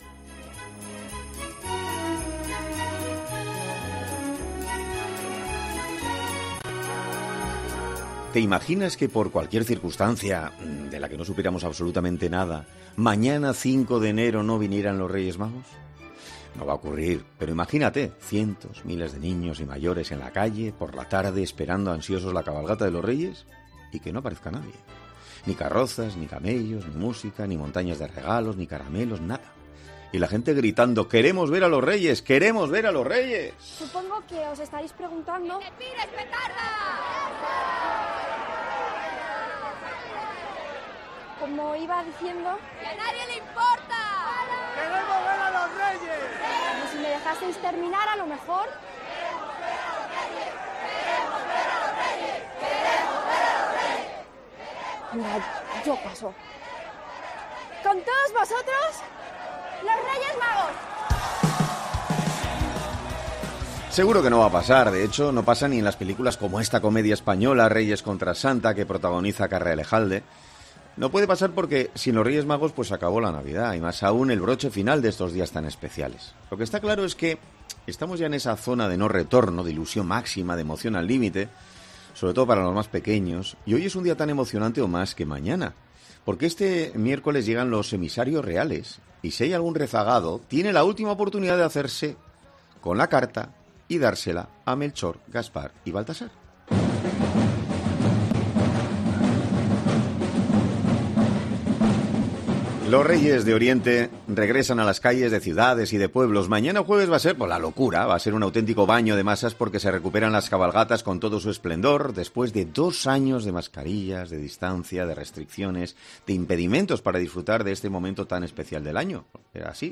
En 'Herrera en COPE' hablamos con Andrea Levy, delegada de cultura del área de turismo y deporte de Madrid